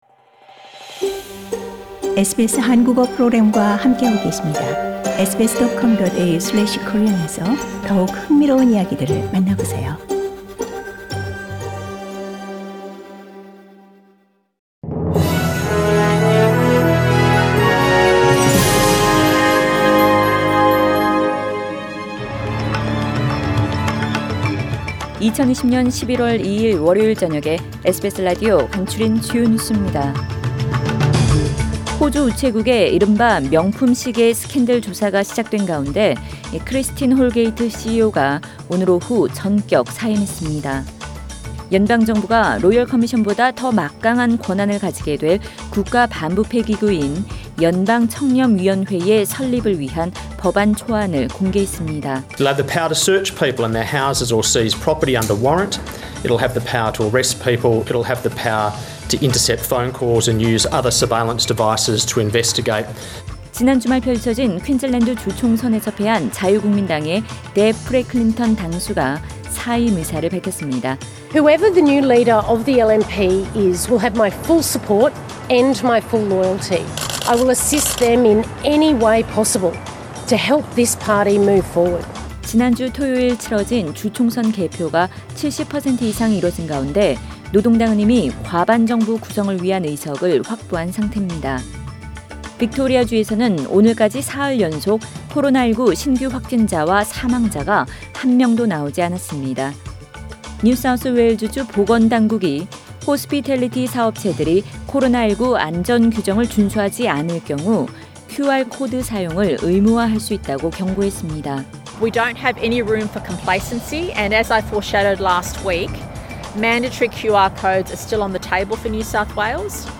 SBS News Outlines…2020년 11월 2일 저녁 주요 뉴스